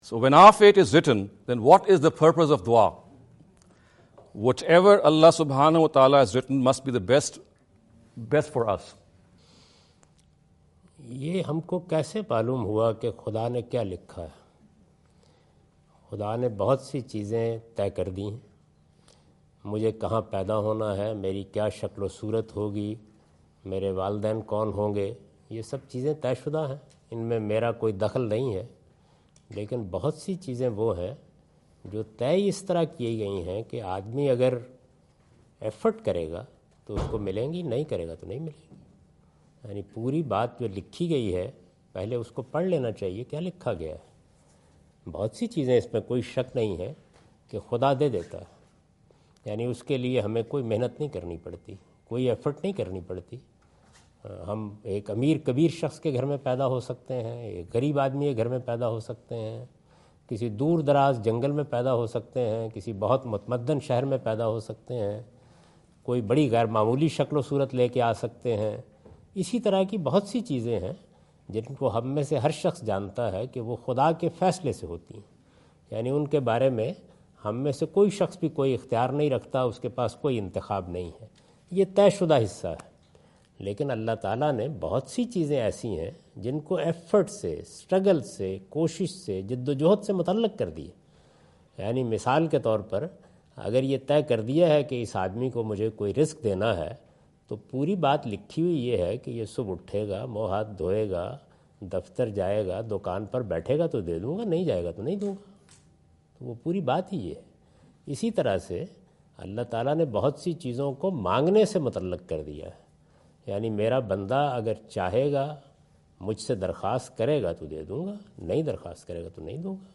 In this video Javed Ahmad Ghamidi answer the question about "why dua if Allah does the best for us?" asked at East-West University Chicago on September 24,2017.